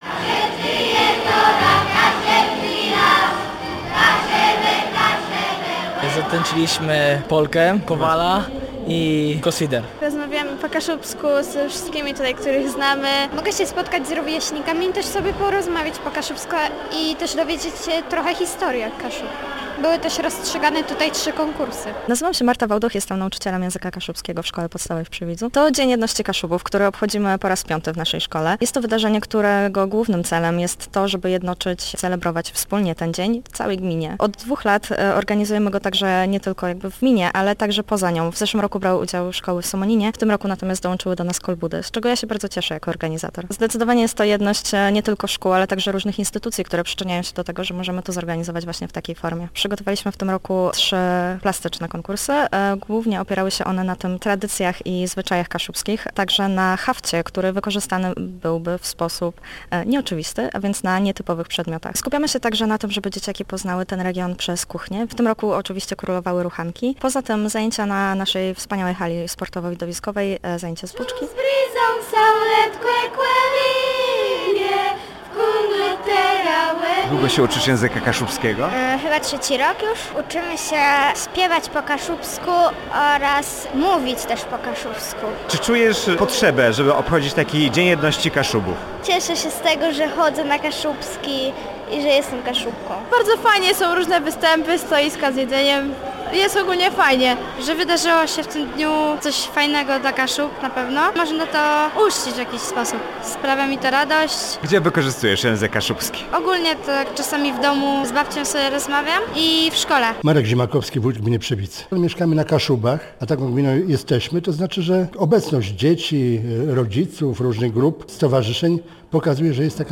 Dlatego na Arenie Przywidz przygotowano wyjątkowy program, gdzie uczniowie wszystkich szkół poczuli ducha Kaszub.
Posłuchaj materiału naszego reportera.